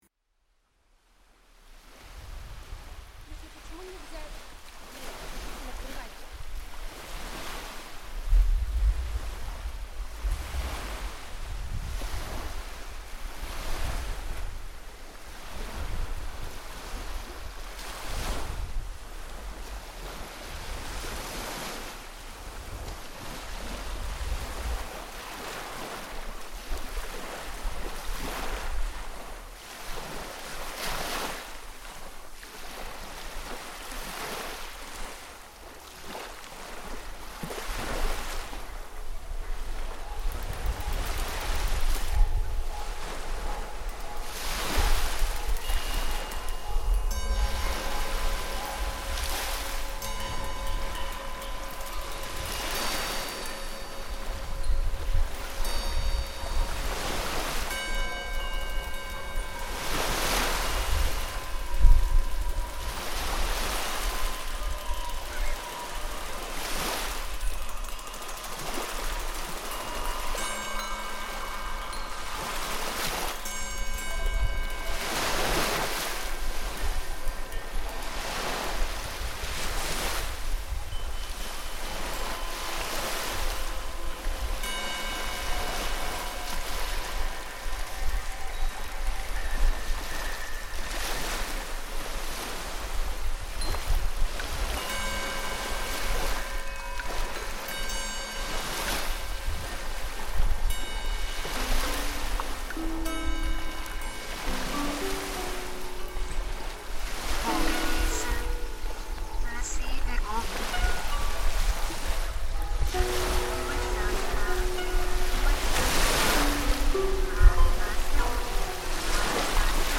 lap harp, percussion
flute